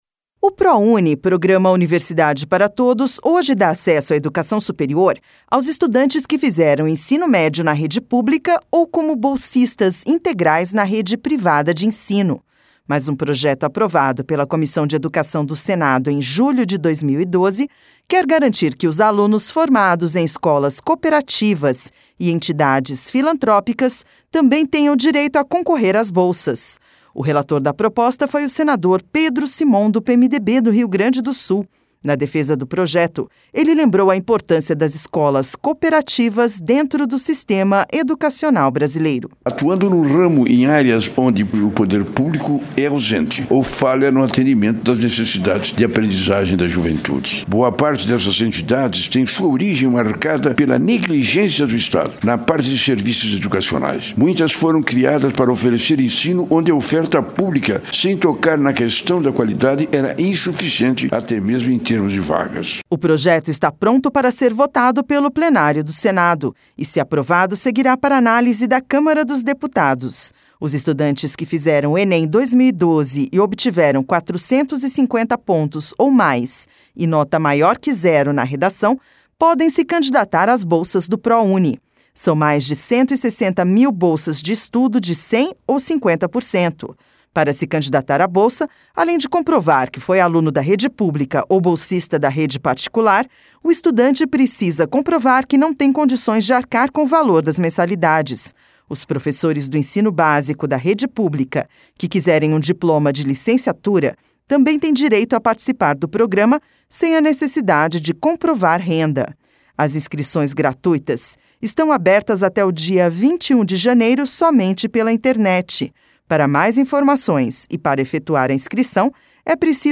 LOC: O PROJETO QUE GARANTE ESSA MUDANÇA FOI APROVADO PELA COMISSÃO DE EDUCAÇÃO E ESTÁ PRONTO PARA VOTAÇÃO NO PLENÁRIO DO SENADO.